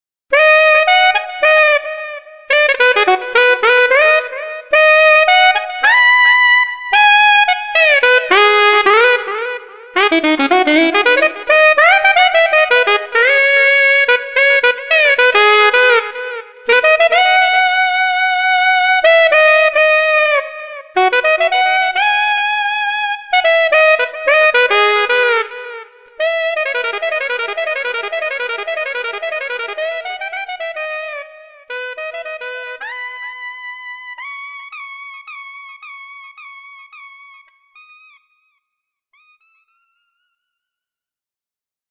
HALion6 : sax
Alto Sax Solo 1